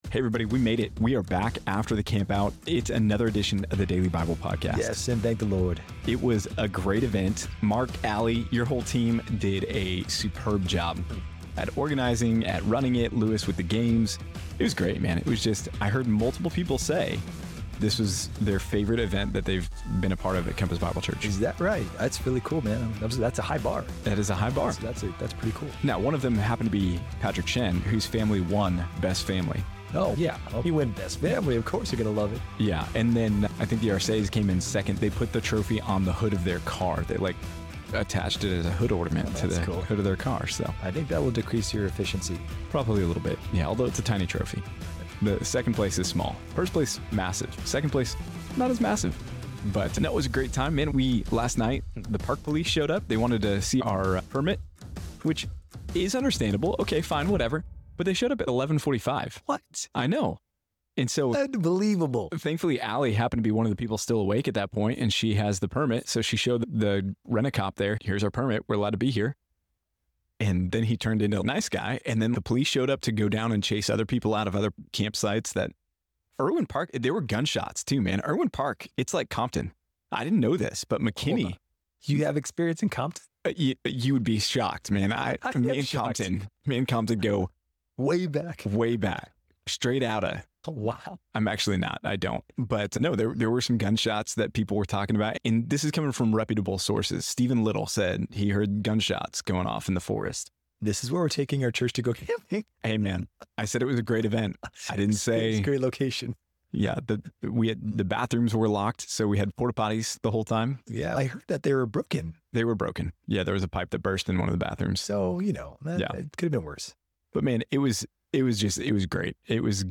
In this episode of the Daily Bible Podcast, the hosts reflect on a successful church camp event and offer gratitude for the efforts made by the organizers.
The conversation transitions to a chronological reading of 1 Samuel chapters 18-20, juxtaposed with Psalms 59 and 11.